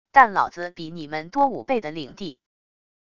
但老子比你们多五倍的领地wav音频生成系统WAV Audio Player